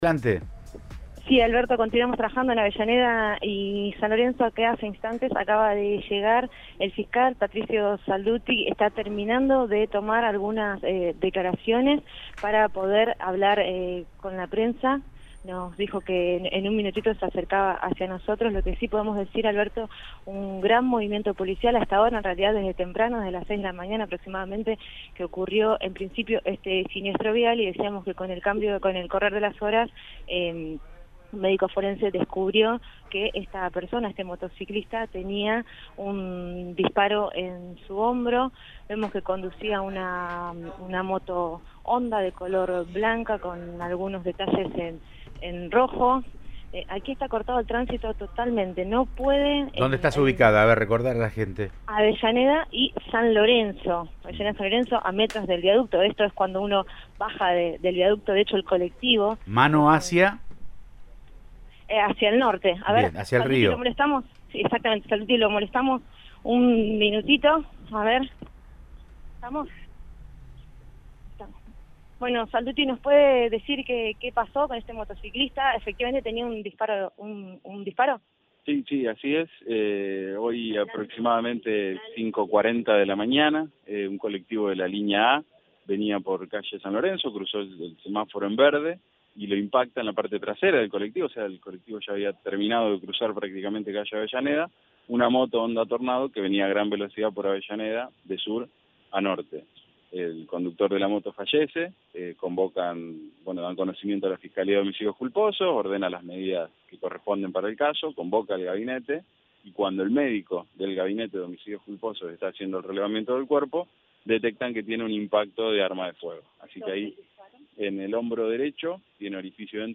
Audio. El fiscal Saldutti dio detalles del motociclista que murió al chocar y estaba baleado
En diálogo con el móvil de Cadena 3 Rosario, en Siempre Juntos, Saldutti explicó: “A las 5.40 un colectivo de la línea A cruza el semáforo en verde y una moto Honda Tornado 250cc lo impacta. Venía de sur a norte”.